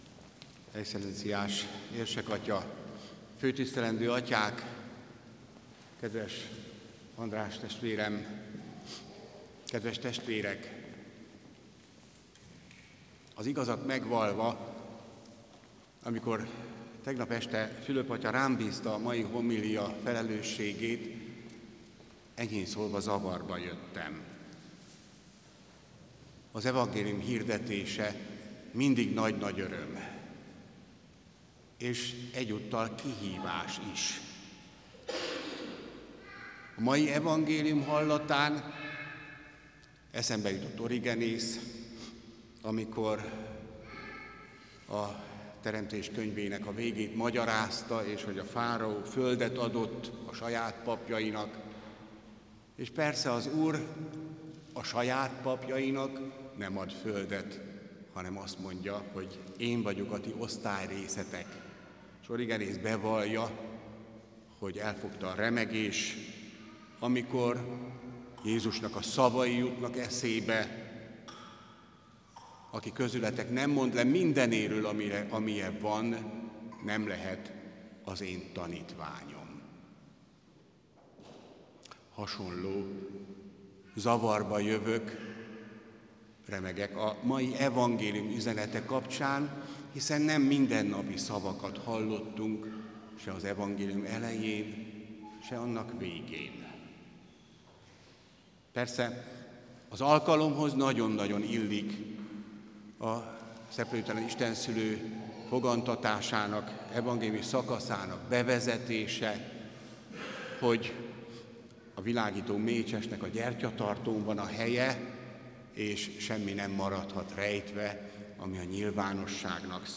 Orosz Atanáz püspök szerint a gyertyatartóra helyezett mécses képe emlékeztet: a pap küldetése nem magától értetődő, hanem szent felelősség. Prédikációjában jogosan tette fel a kérdést: hogyan hirdethetnénk fényt úgy, hogy mi magunk még nem tisztultunk meg teljesen?